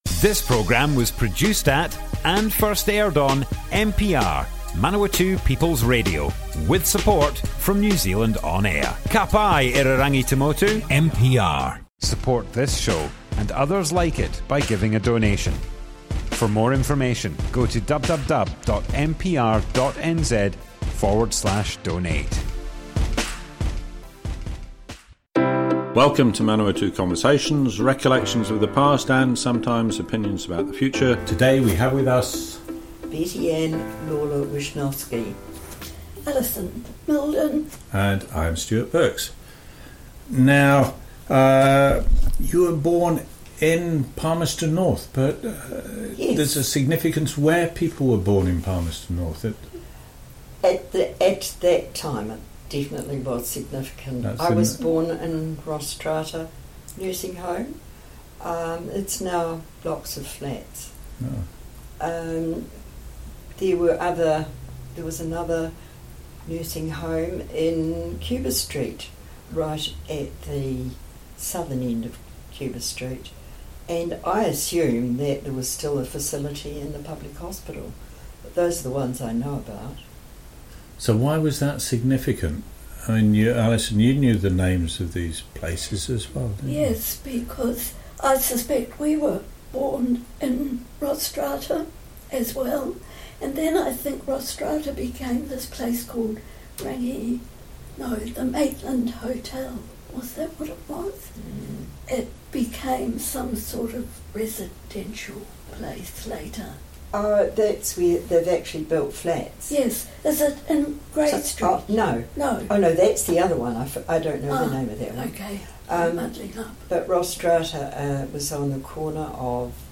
Manawatu Conversations More Info → Description Broadcast on Manawatu People's Radio, 23rd March 2021.
oral history